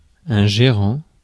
Ääntäminen
France: IPA: [ʒe.ʁɑ̃]